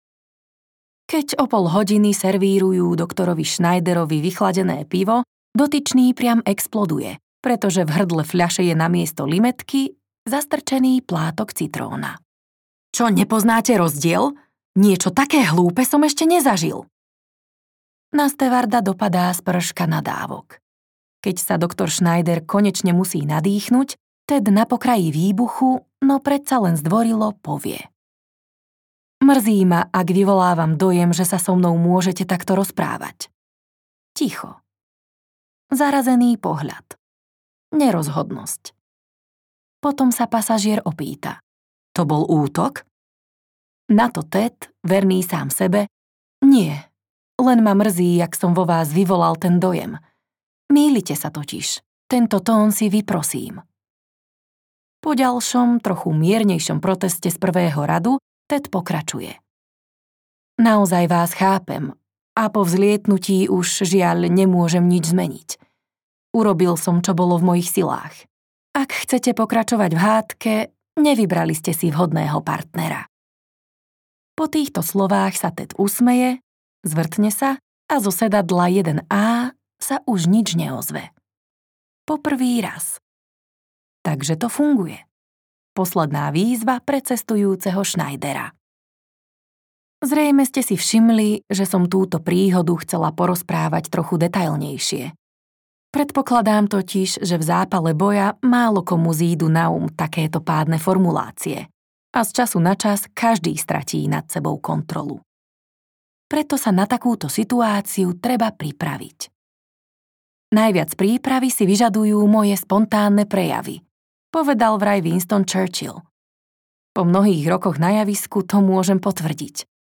50 viet, ktoré vám uľahčia život audiokniha
Ukázka z knihy